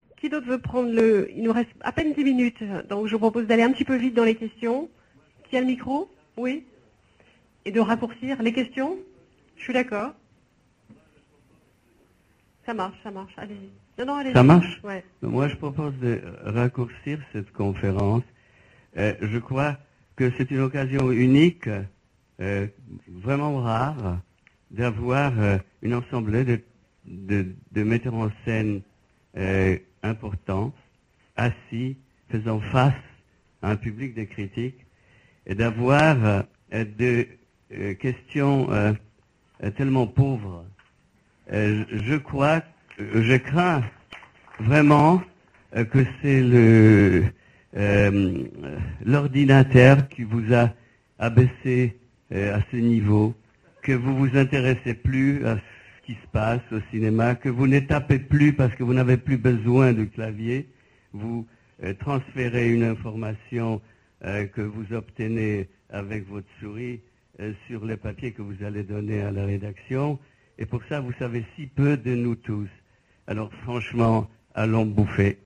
[Polanski quitte la conférence de presse] Proclamation de Roman Polanski, sur la fin de la conférence de presse suivant Chacun fait son cinéma (Cannes, dimanche 20 mai 2007) :